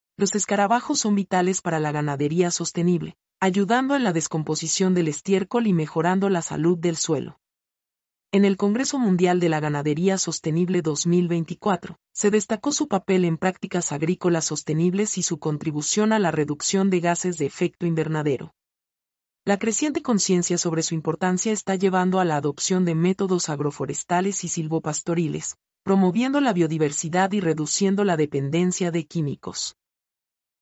mp3-output-ttsfreedotcom-23-1.mp3